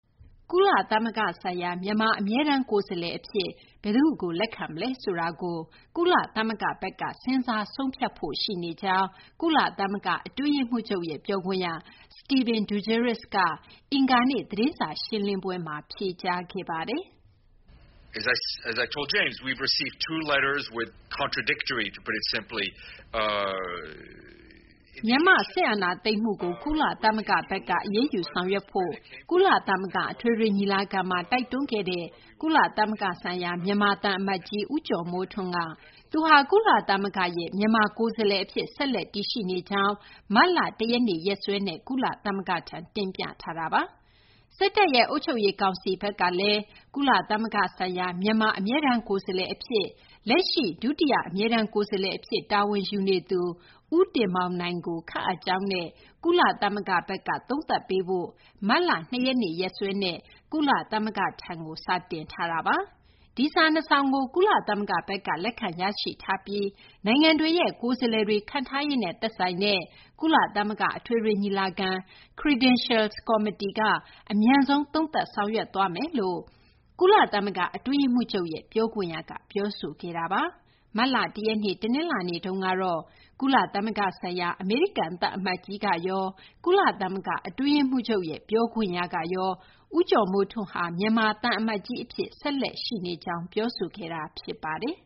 ကုလသမဂ္ဂဆိုင်ရာ မြန်မာ အမြဲတမ်း ကိုယ်စားလှယ် အဖြစ် ဘယ်သူ့ကို လက်ခံမလဲ ဆိုတာကို ကုလသမဂ္ဂဘက်က စဉ်းစား ဆုံးဖြတ်ဖို့ ရှိနေကြောင်း ကုလသမဂ္ဂ အတွင်းရေးမှူးချုပ်ရဲ့ ပြောခွင့်ရ Stéphane Dujarric က အင်္ဂါနေ့ သတင်းစာ ရှင်းလင်းပွဲမှာ ဖြေကြားခဲ့ပါတယ်။